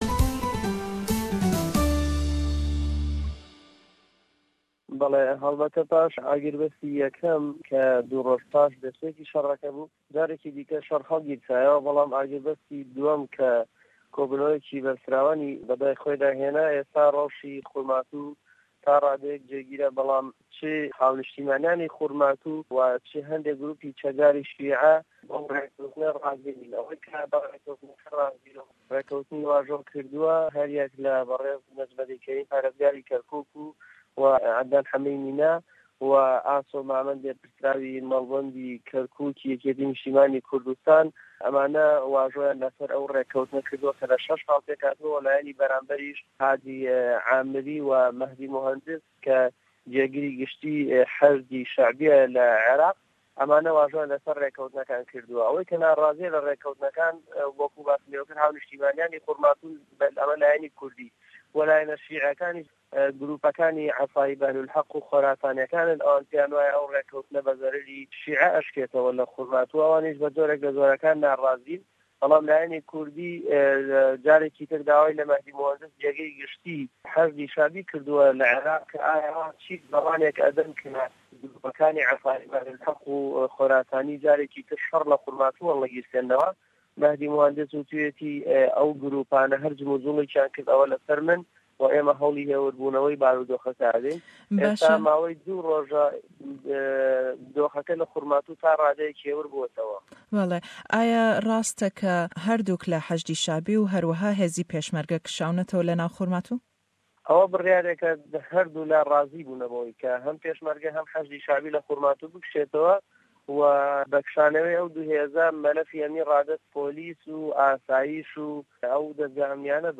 Le em hevpeyvîn e da